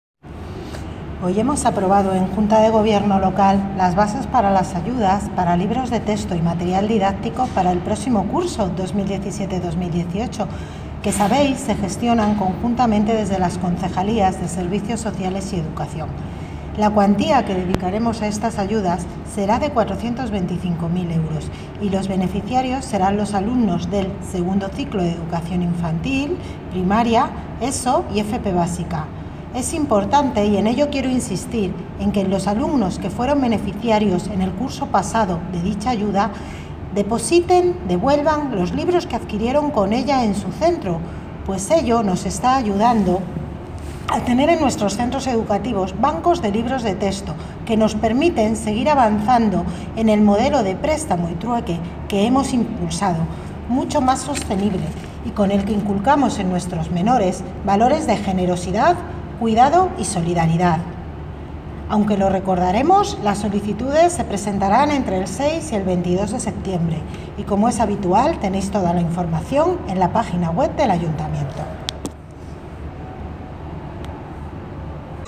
Audio - Isabel Cruceta (Concejala de Educación ) Sobre Becas Libros Texto